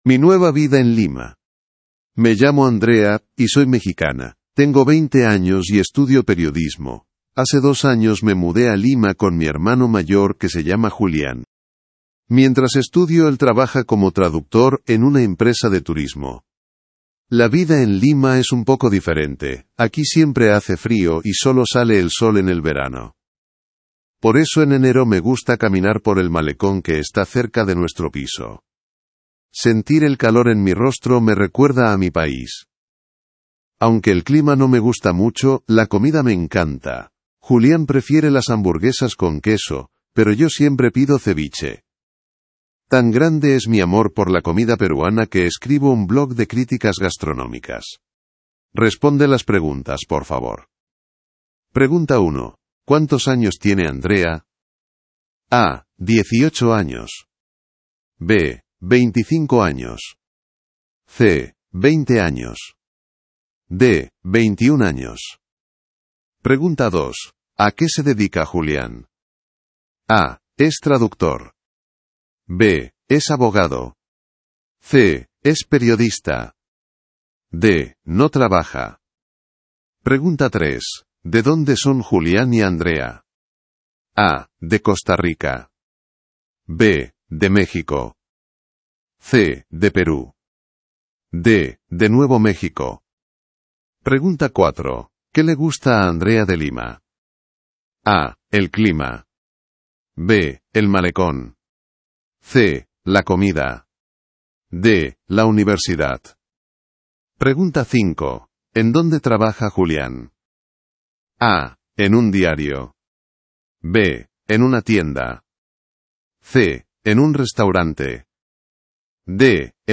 Hiszpania